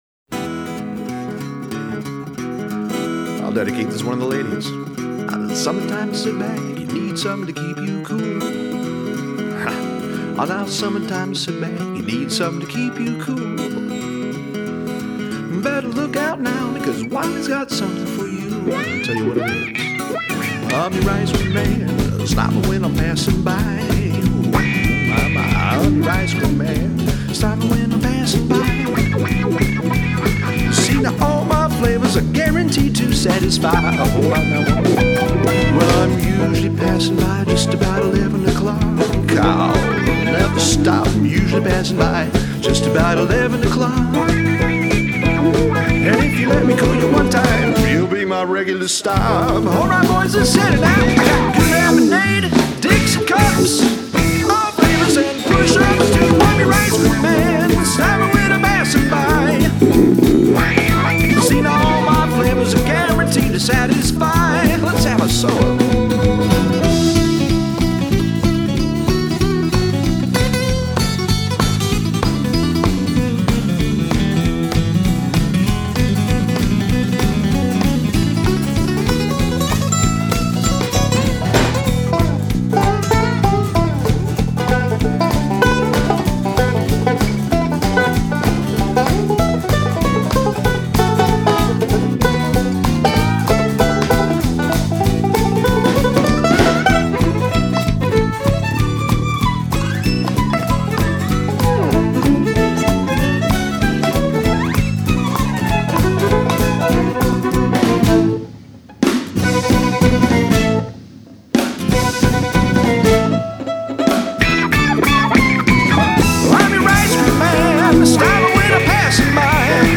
banjo
Listen for his innovative solo late in this one.